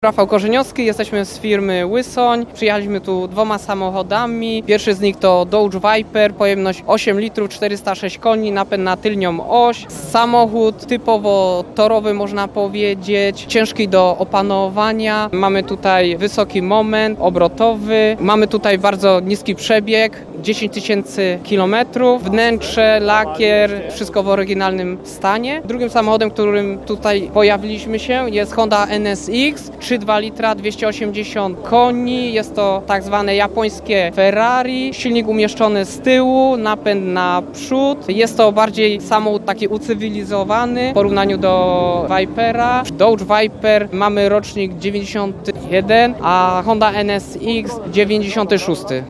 Ryk silników, pisk opon i maszyny takie, że dech zapiera. W hali pod Dębowcem w Bielsku-Białej trwa Moto Show 2023 – największa impreza motoryzacyjna w tej części Europy.